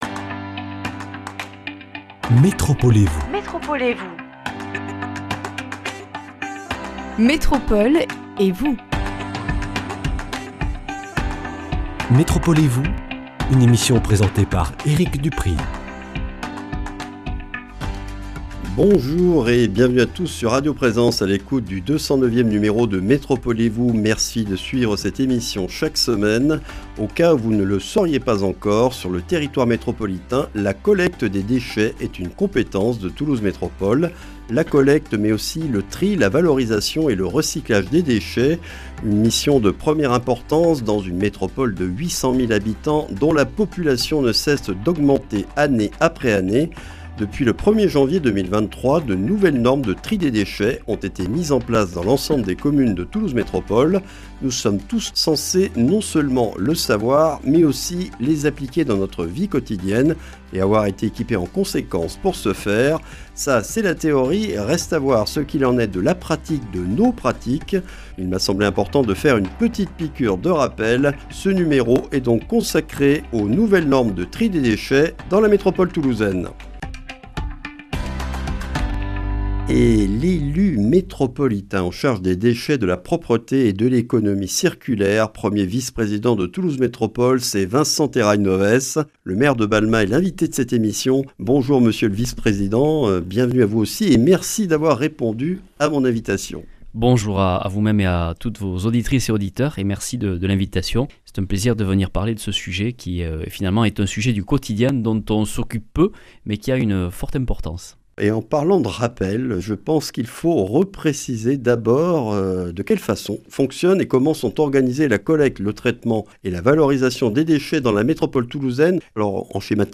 Vincent Terrail-Novès, maire de Balma, 1er vice-président Toulouse Métropole chargé de l’Économie circulaire, des Déchets et de la Propreté, est l’invité de ce numéro. Depuis le 1er janvier 2023, de nouvelles normes de tri des déchets sont en vigueur dans la métropole toulousaine. Présentation de ses évolutions et des prochaines à venir concernant les biodéchets à partir de 2024.